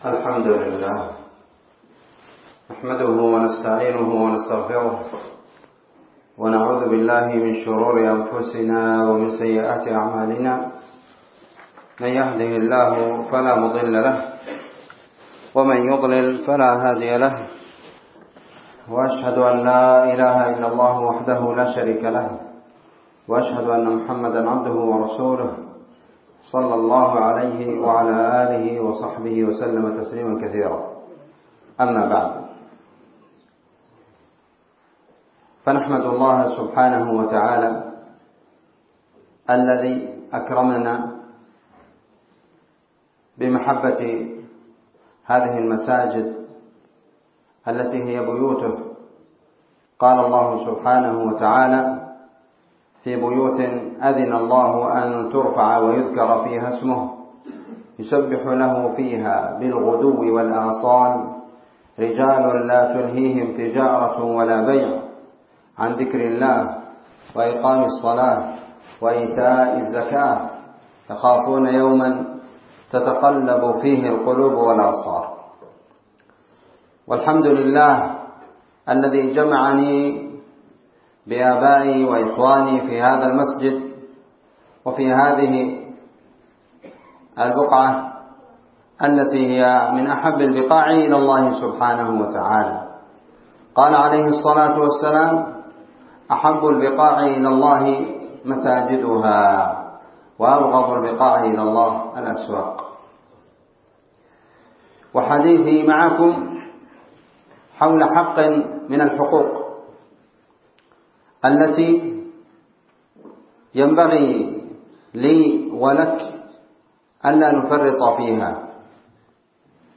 كلمة بعنوان حقوق الموتى على الأحياء وأقسام زيارتهم 6 جمادى الآخرة 1444